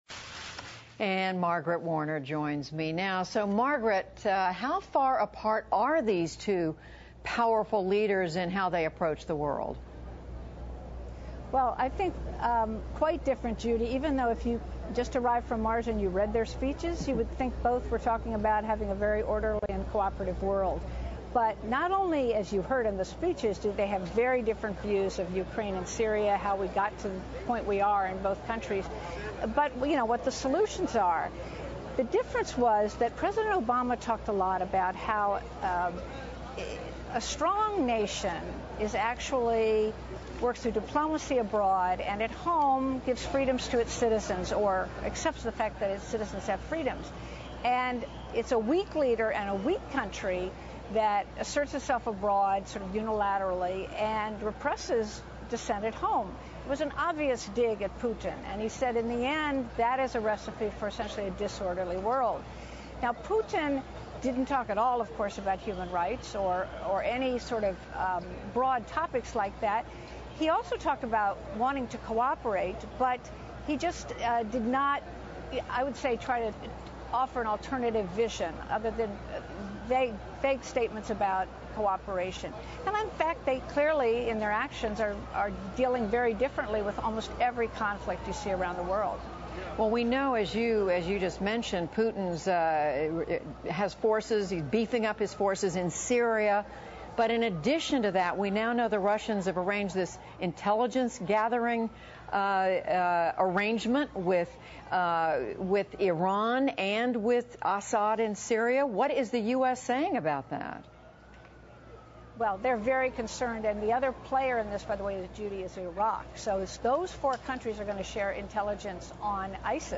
PBS高端访谈:美国和俄罗斯对ISIS战略有较大分歧 听力文件下载—在线英语听力室